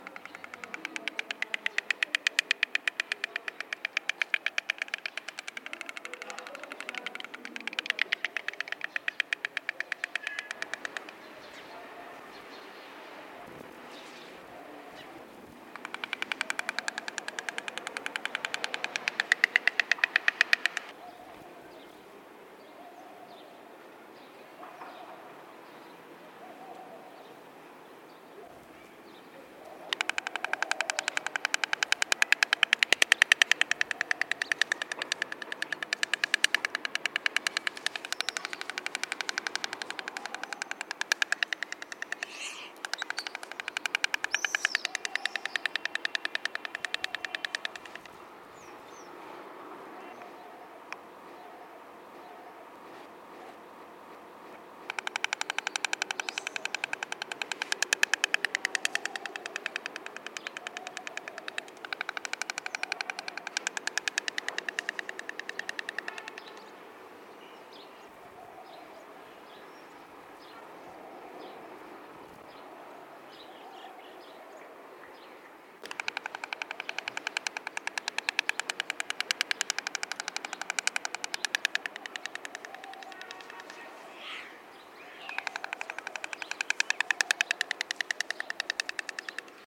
На этой странице собраны звуки аиста в разных ситуациях: крики, щелканье клювом, шум крыльев.
Аист в зоопарке пощелкивает клювом